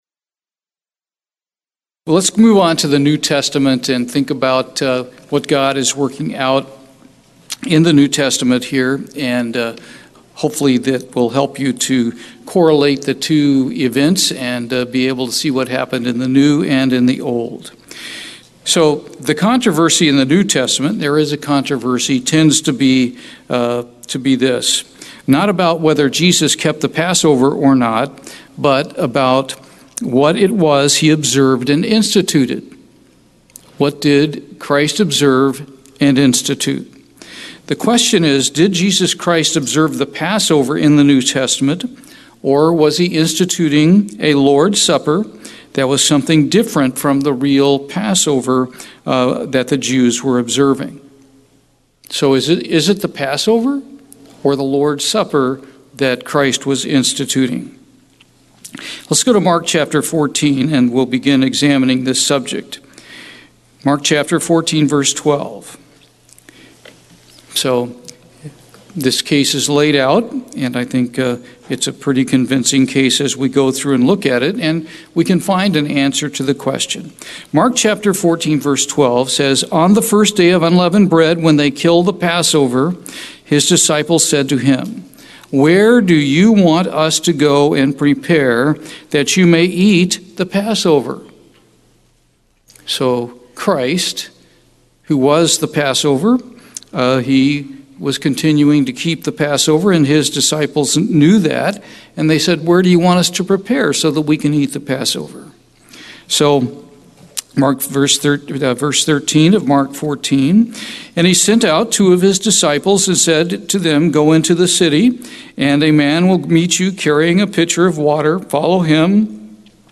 In this second split sermon